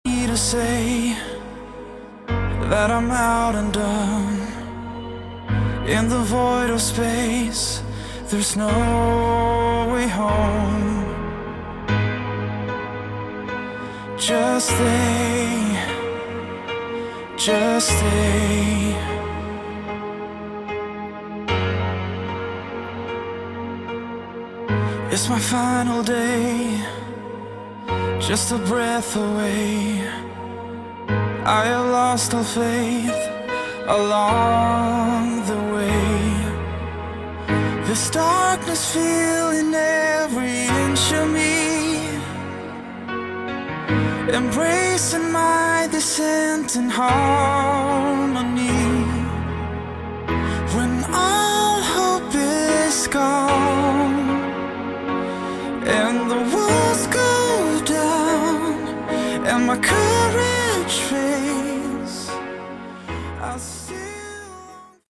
Category: AOR / Melodic Rock
lead vocals
lead guitar
rhythm guitar
keyboards
bass
drums